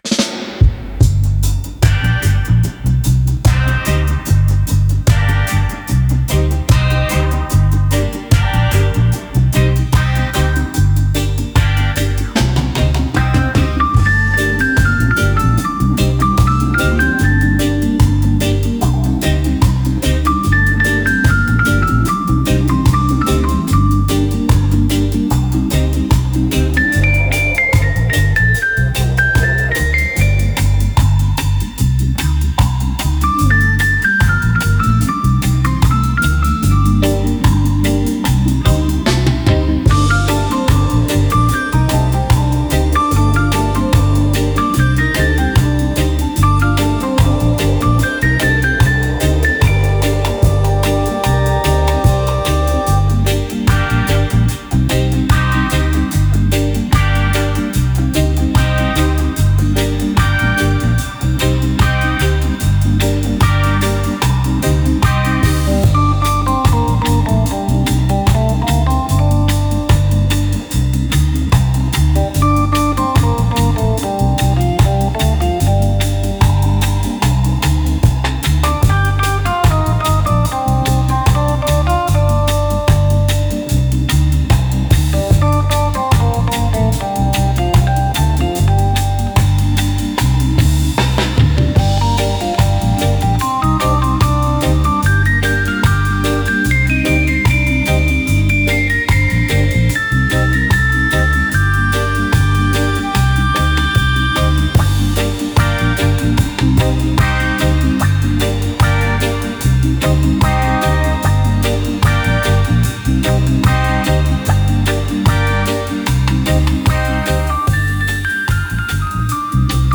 An original AI-assisted dub production